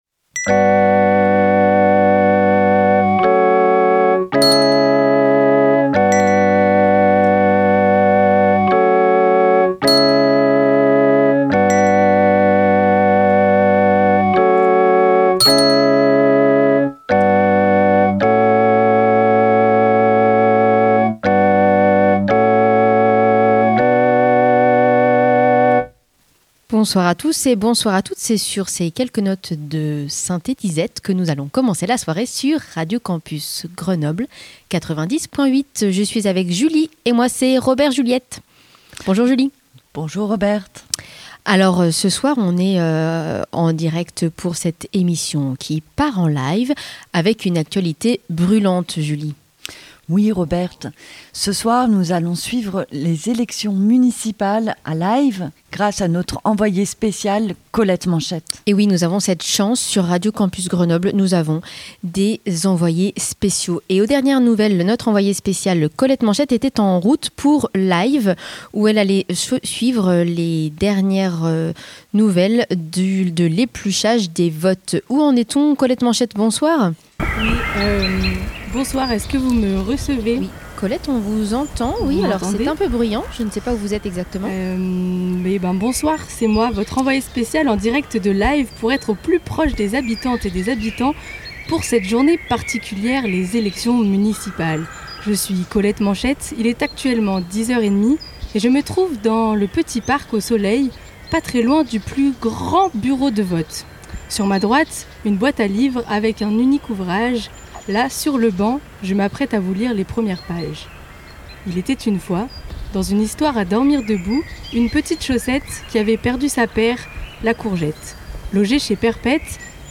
Dimanche 15 mars, nous sommes parti.e.s à l’aventure, loin, en Live.
L’adrénaline de jouer une partition ensemble, où tous les formats sont permis, se superposent, se répondent, se confondent, dissipent la frontière de l’anticipé et du spontané, du prévu et de l’imprévu, de la fiction et du réel.